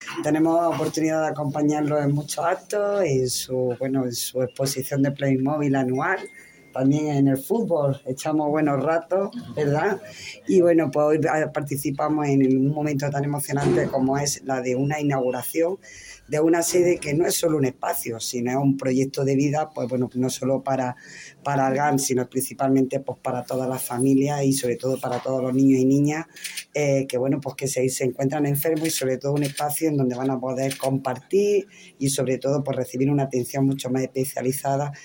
Esta tarde ARGAR ha inaugurado su sede en un acto que ha contado con un amplio respaldo institucional.